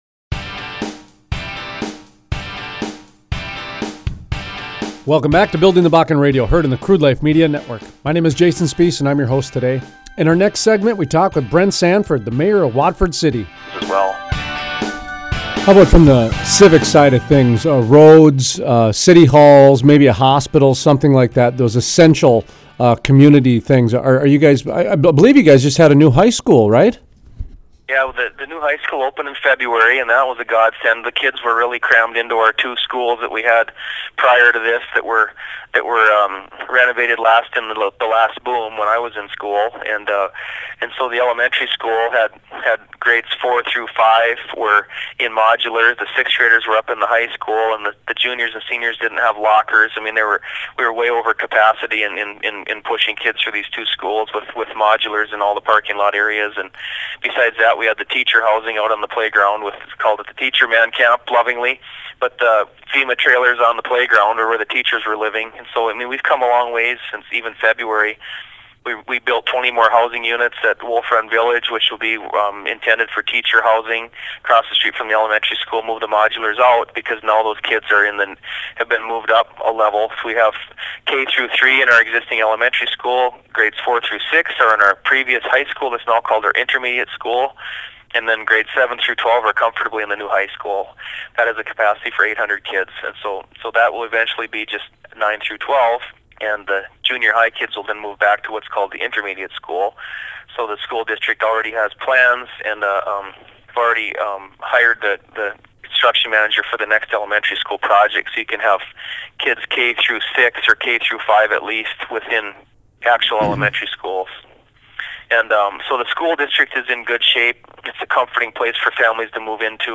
Interviews: Brent Sanford, Mayor, Watford City Talks about the new growth in Watford City from main street to schools to water pipes. He even talks about how you can get a gyro in Watford City now.